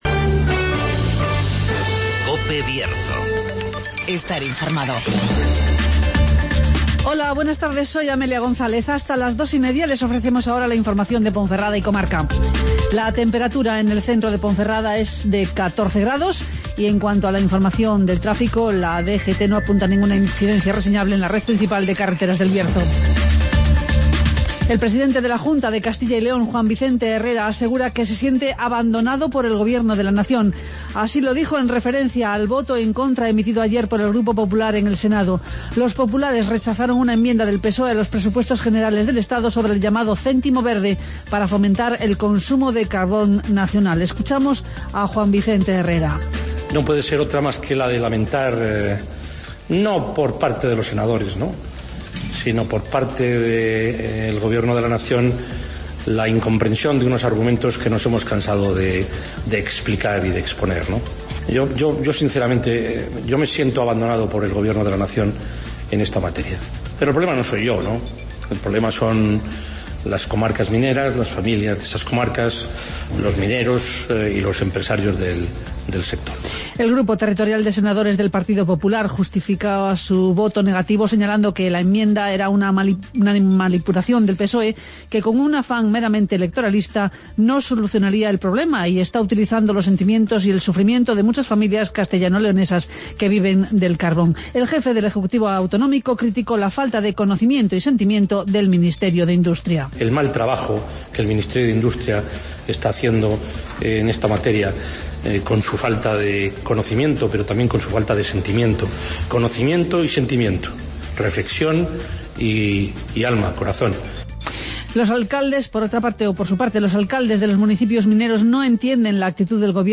Escucha las noticias de Ponferrada y comarca en el Informativo Mediodía de COPE Bierzo